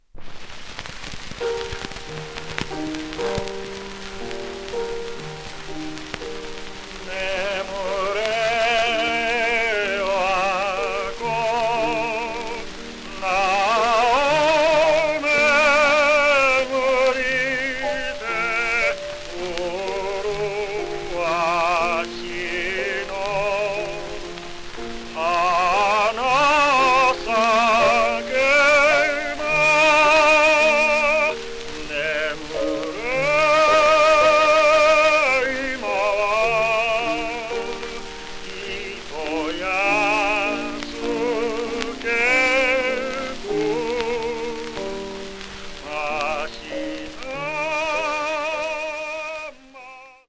すべて電気録音。
1933年1月30日ロンドン、アビーロード録音(電気録音)ドイツ語歌唱